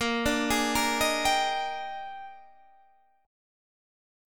A#13 chord